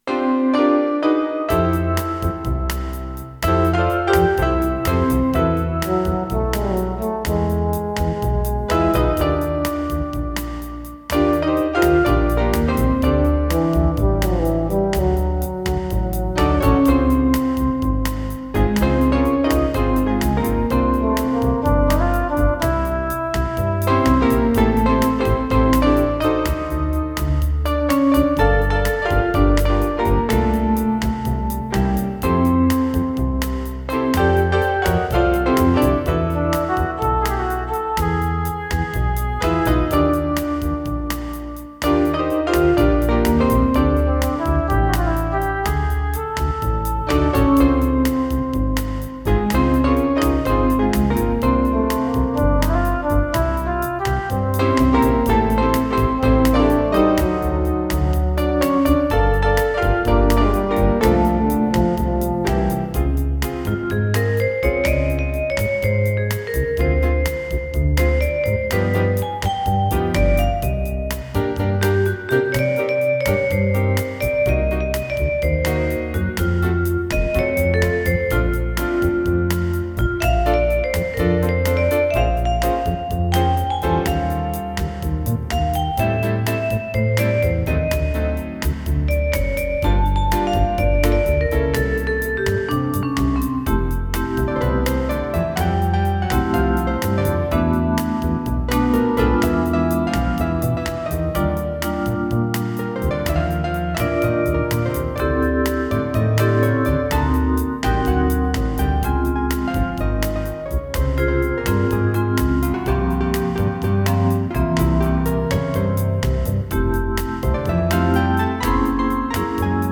Roland MT-32 and Sound Canvas Enhanced version.